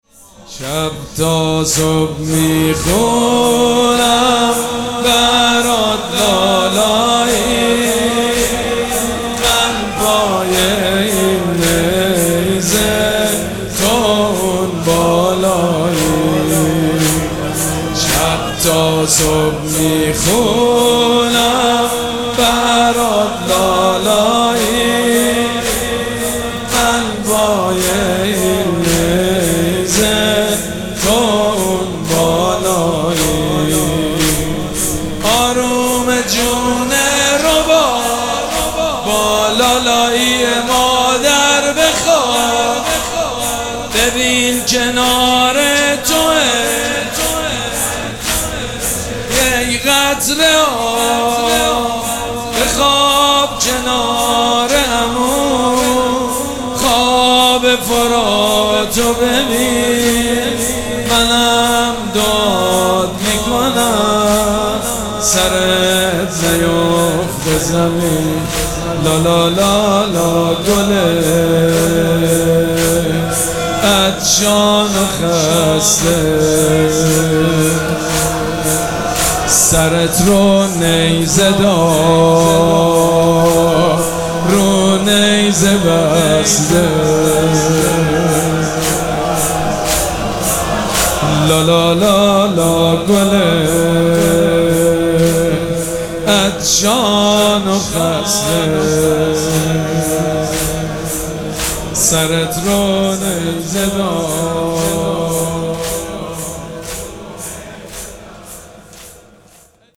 مراسم عزاداری شب هفتم محرم الحرام ۱۴۴۷
مداح
حاج سید مجید بنی فاطمه